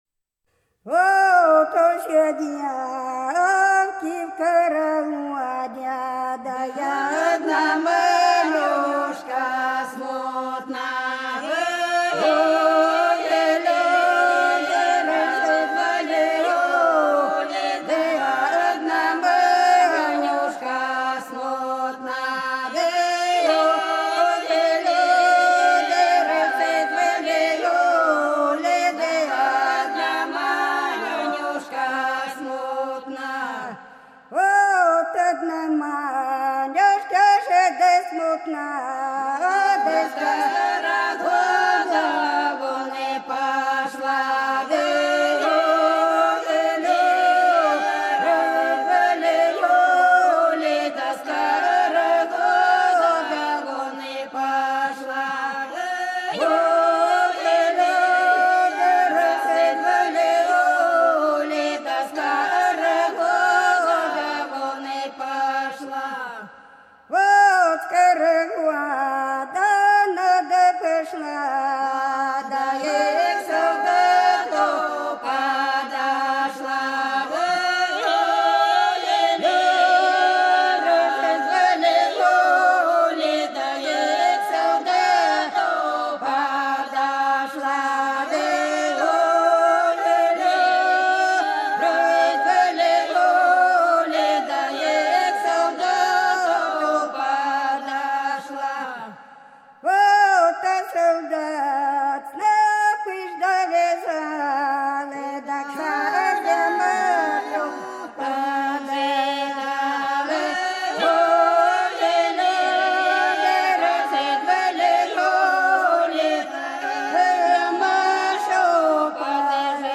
Долина была широкая (Поют народные исполнители села Нижняя Покровка Белгородской области) Ох, все девки в карагоде - плясовая